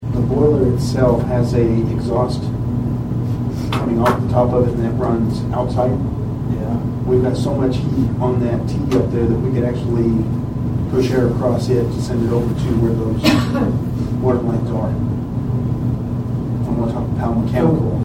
The Nowata County Commissioners held a short meeting on Monday morning at the Nowata County Annex.
Chairman Paul Crupper discussed a frozen pipe issue at the courthouse and a way to fix it.